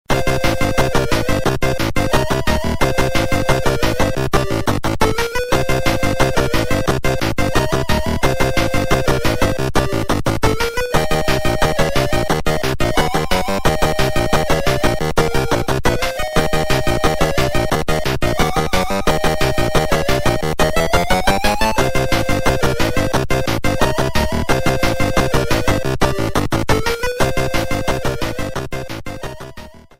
Stages 1-8, 2-12, 3-8, 4-12, 5-12, 6-8, 7-12 and 8-16 theme
Fair use music sample